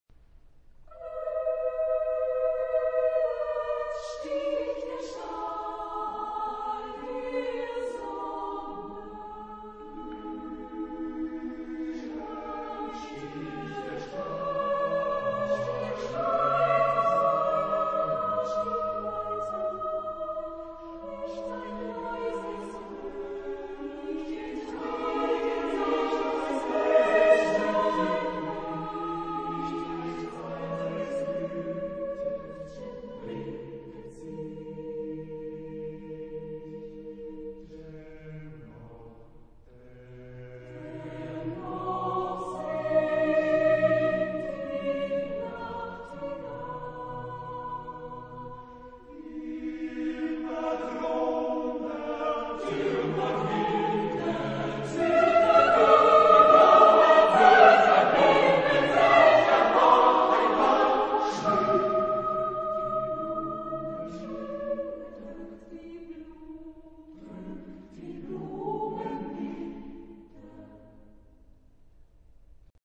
Genre-Stil-Form: Chorlied ; Zyklus ; weltlich
Chorgattung: SATB  (4 gemischter Chor Stimmen )
Tonart(en): a-moll
von Vocalisti Rostochiensis gesungen
Aufnahme Bestellnummer: 7. Deutscher Chorwettbewerb 2006 Kiel